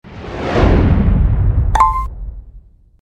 礼物UI弹窗3.MP3